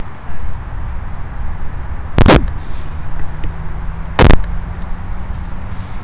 Today I recorded a sound clip when flying a quadcopter using my Sony Xperia Z2 smartphone and an app called Smart Voice but listening to the clip afterwards there are some “crackling” noise heard every now and then and I’m not sure if it’s caused by the wind, some other interference, a bad app or simply because the internal microphone in the smartphone isn’t that great for these types or outside audio recordings.
It’s possible to reduce the bursts of loud crackles so they are the same amplitude as the rest of the audio …